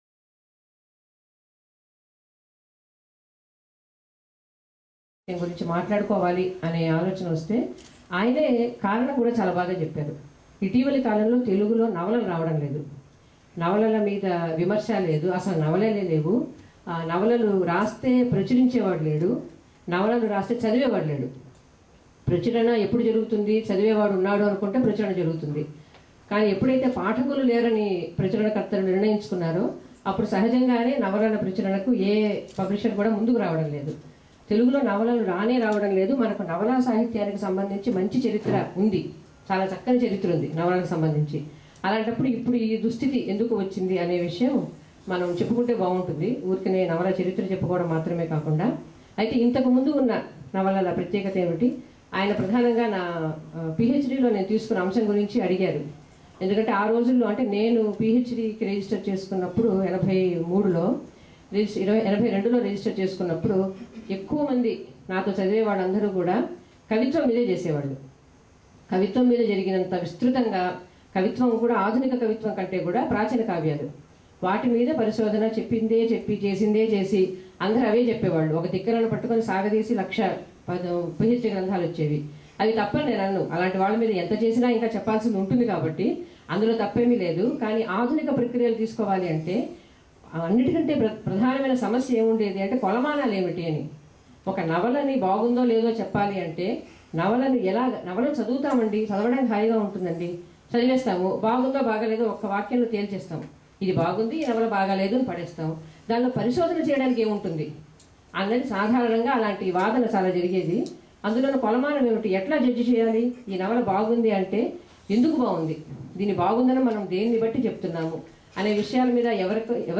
శాంతాక్లారా, కాలిఫోర్నియా లో జరిగిన సాహితీ సభలో తెలుగు నవలా సాహిత్యంపై వారి ప్రసంగం ఇది.